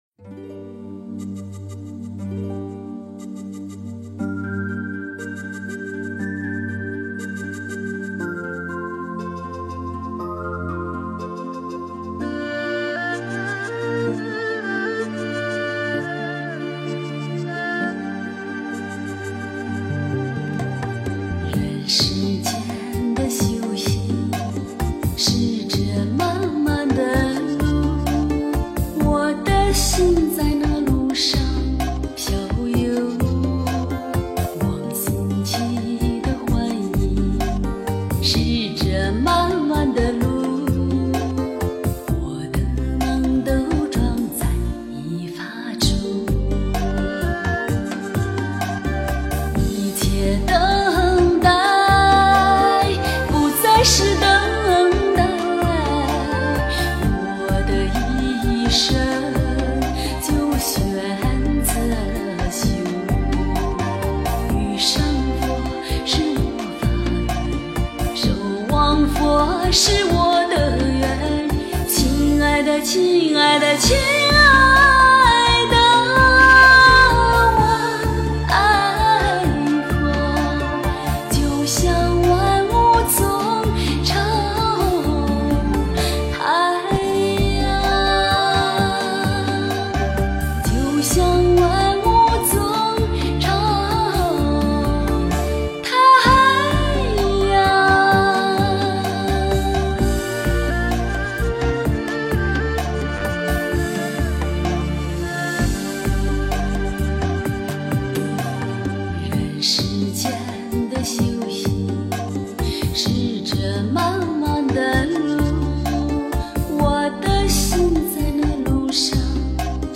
遇见佛是我法缘 诵经 遇见佛是我法缘--佛教音乐 点我： 标签: 佛音 诵经 佛教音乐 返回列表 上一篇： 永恒是佛性 下一篇： 遇上你是我的缘 相关文章 45毒箭--诚敬仁和 45毒箭--诚敬仁和...